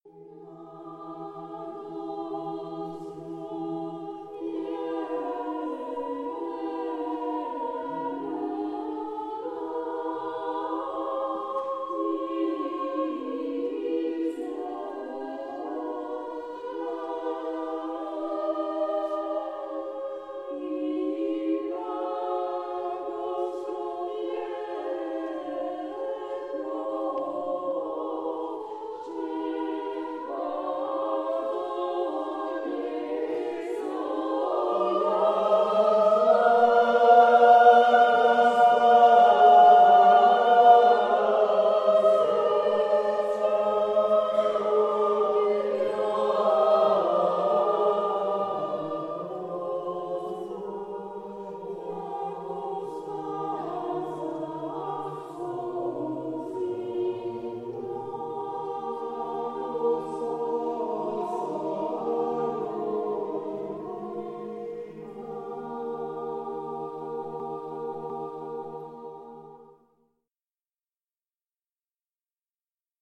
Musique chorale des pays de l'Est
Extraits de la restitution de fin de stage
dans l'église de Saint-Hugues de Chartreuse, Musée Arcabas, le 4 juillet 2023